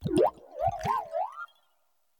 Cri d'Olivado dans Pokémon Écarlate et Violet.